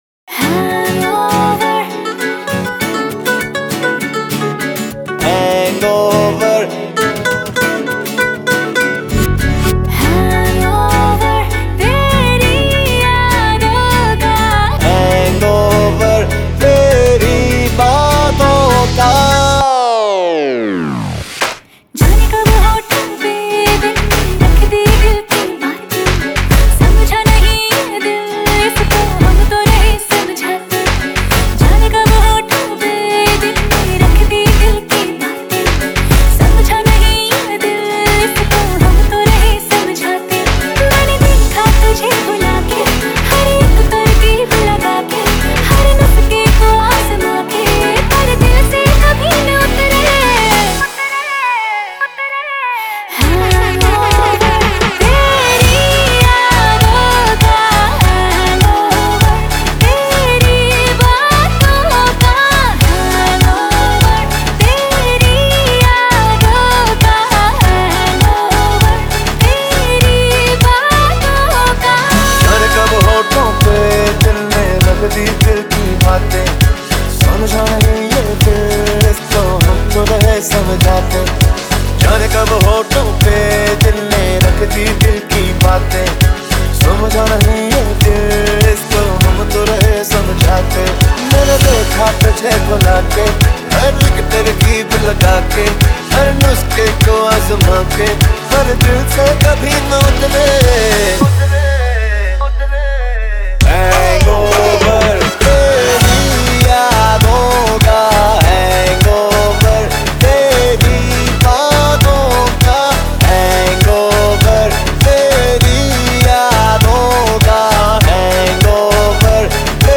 Bollywood Mp3 Music 2014